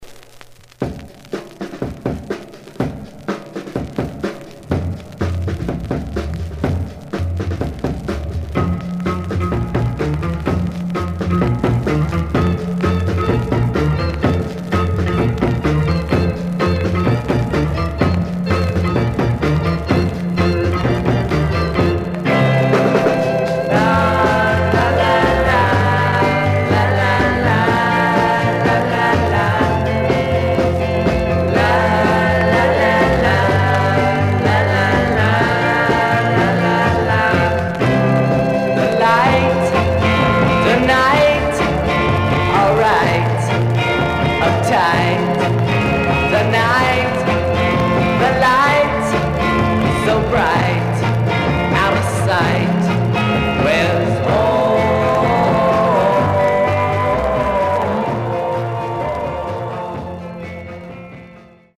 Surface noise/wear
Mono
Garage, 60's Punk